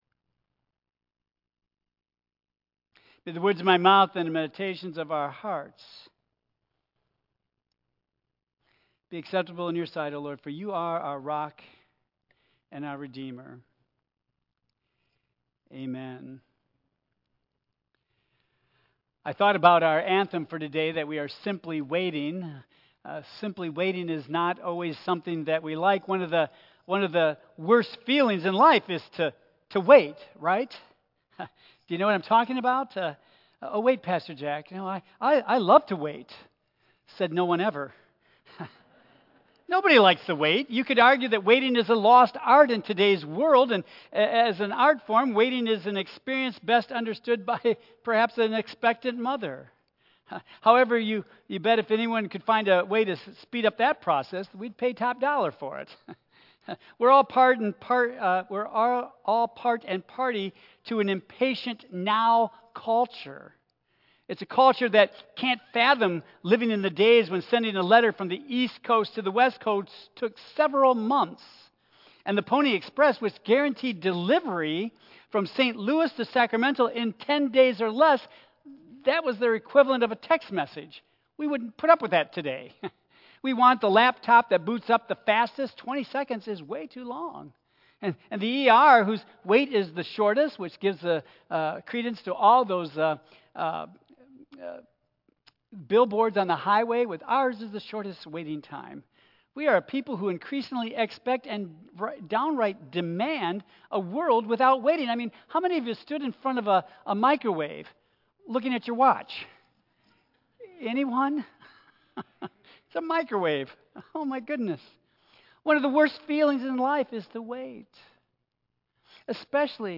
Tagged with Michigan , Sermon , Waterford Central United Methodist Church , Worship Audio (MP3) 9 MB Previous Is Peace Even Possible Today?